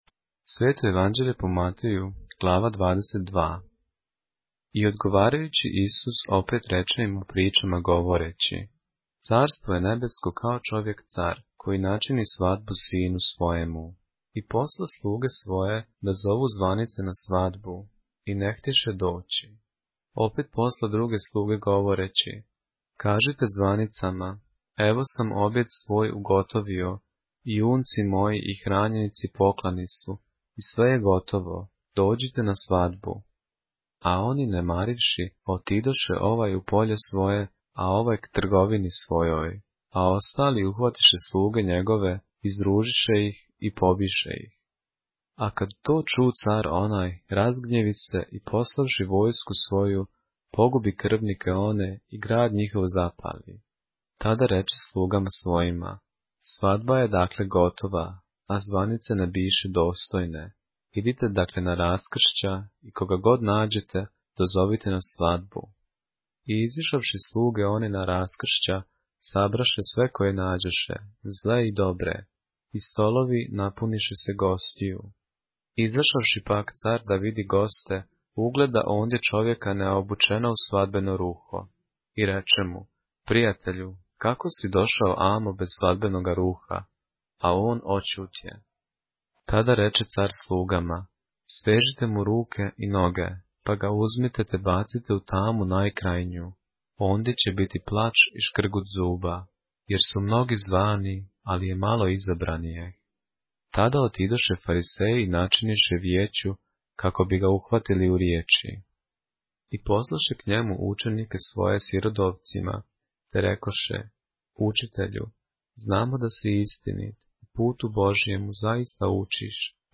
поглавље српске Библије - са аудио нарације - Matthew, chapter 22 of the Holy Bible in the Serbian language